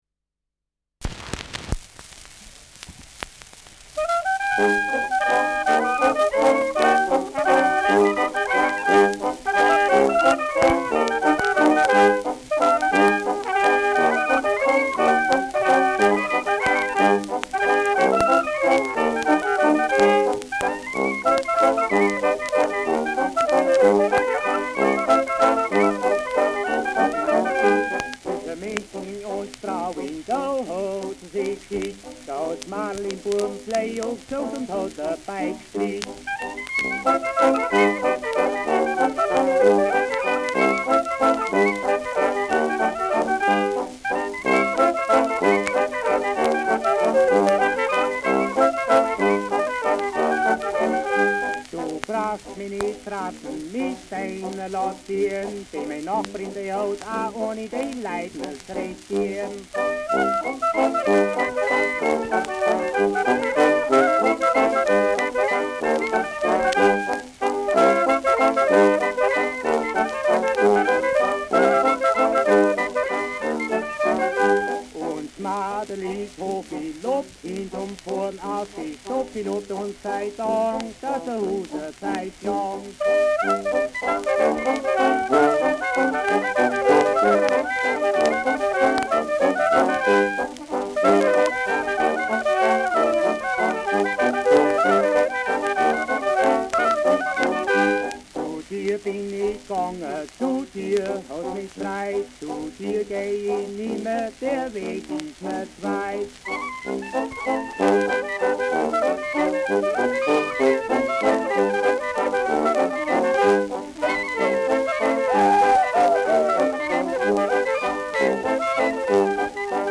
Zwischen München und Straubing : Ländler mit Gesang
Schellackplatte
Leichtes Grundrauschen : Gelegentlich leichtes bis stärkeres Knacken
Kapelle Die Alten, Alfeld (Interpretation)